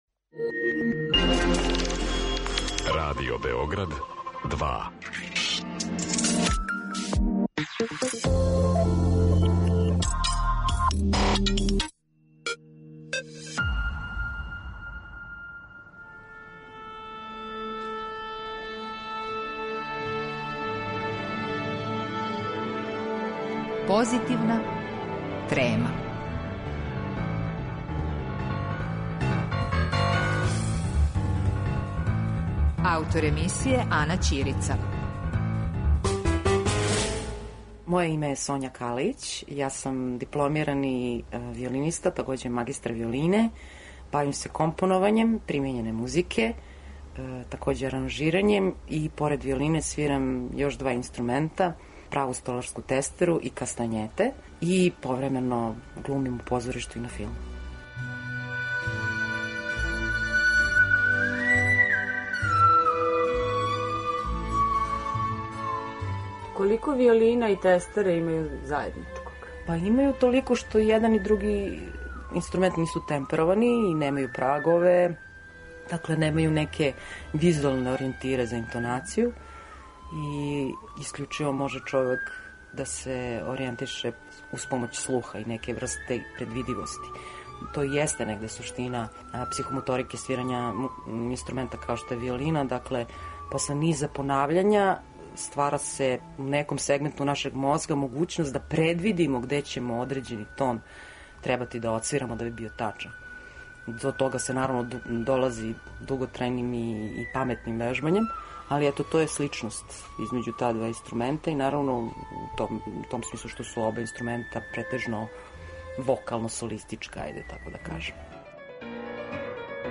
Гошћа Позитивне треме